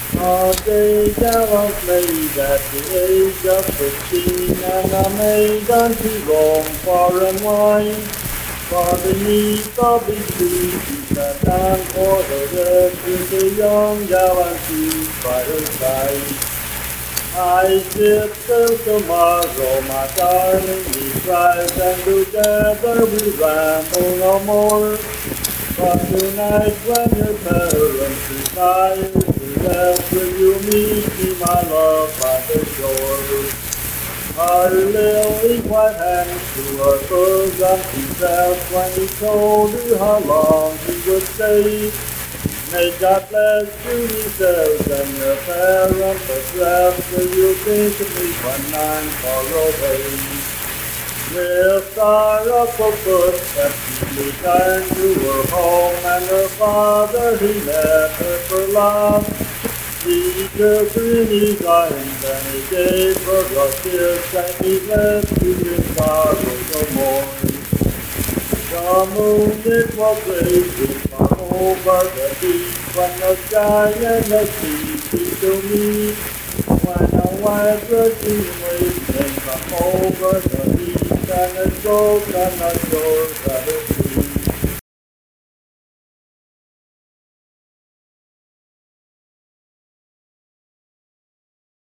Unaccompanied vocal music performance
Voice (sung)
Randolph County (W. Va.)